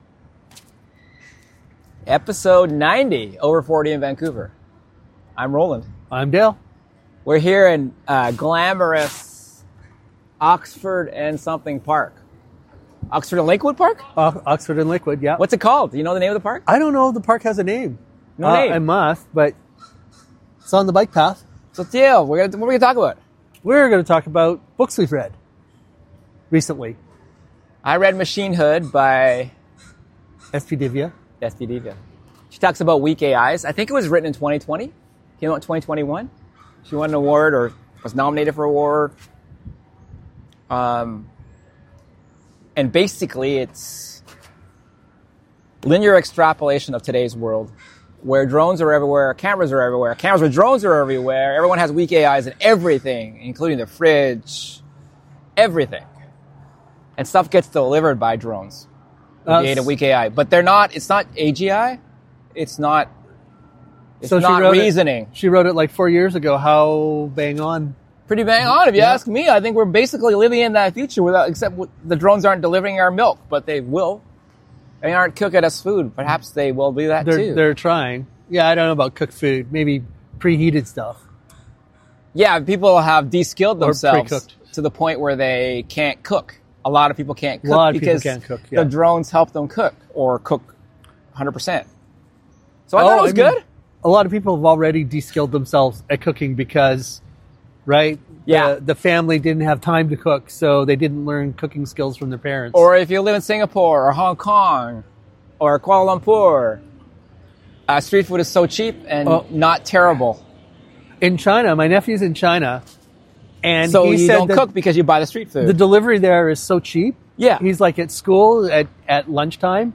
Recorded at Oxford Park (Oxford and Lakewood, East Vancouver, Canada) on a super warm (thanks climate change) last day of February 2025